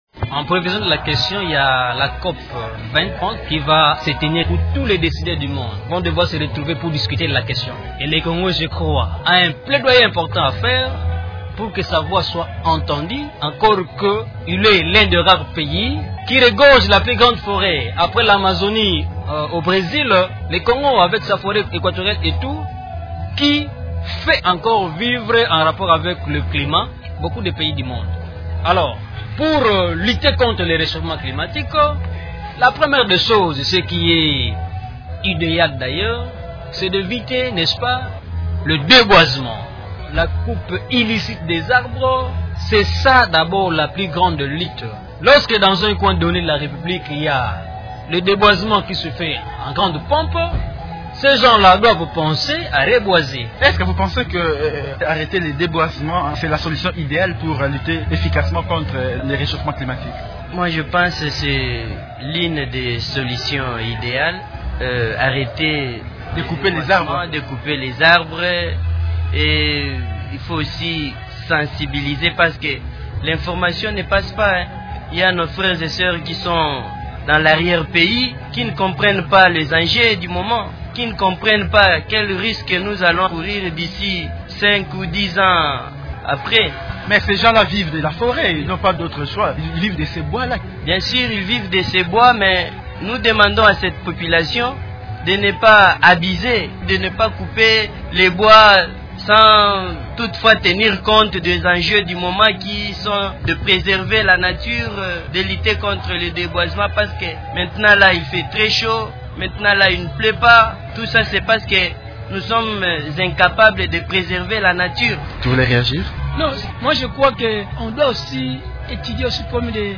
Alors que les dirigeants du monde discutent du réchauffement climatique à Paris à l’occasion de la conférence sur le climat qui s’est ouverte à Paris (COP21) lundi 30 novembre, des jeunes congolais commentent les enjeux de cette rencontre.